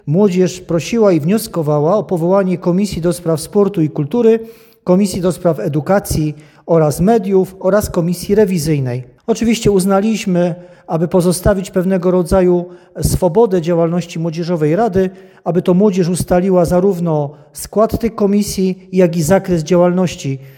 Nowe zapisy umożliwiają powoływanie komisji merytorycznych, co ma na celu usprawnienie działalności rady i lepsze odpowiadanie na potrzeby młodzieży w regionie, mówi wicestarosta żywiecki Stanisław Kucharczyk.